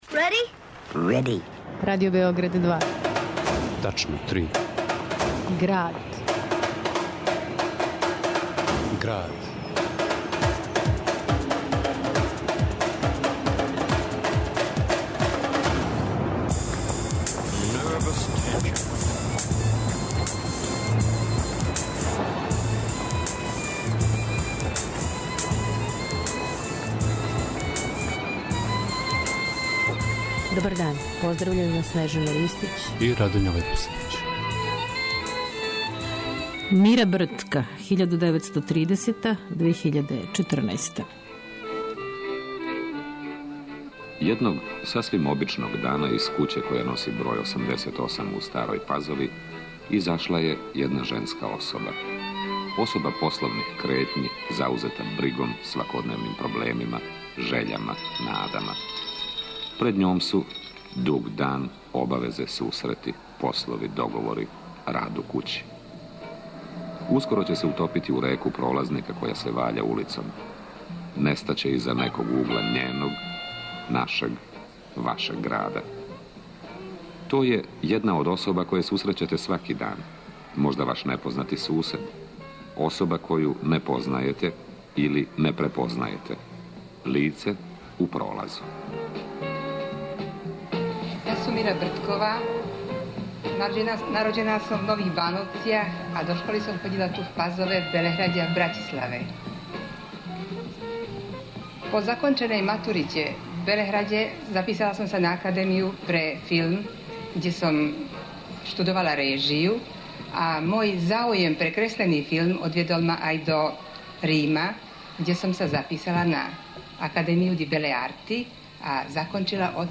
уз звучне инсерте из документарног филма